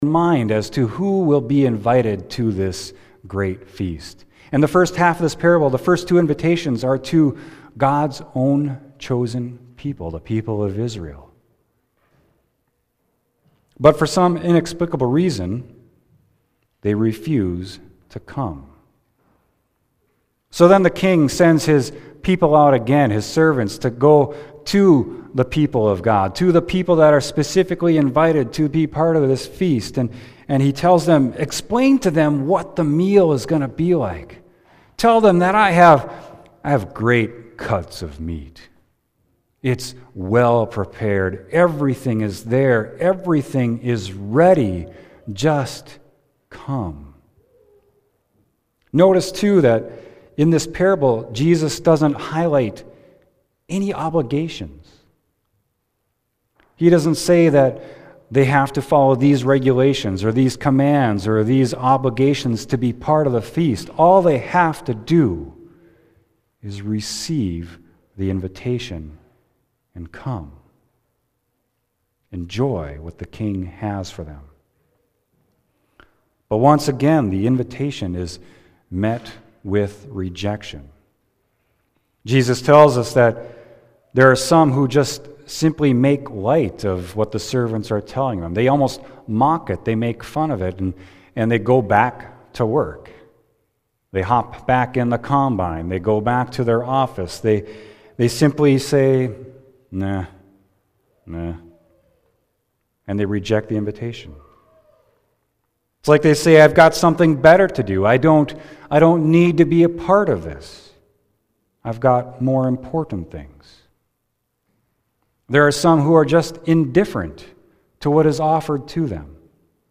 Sermon: Matthew 22.1-14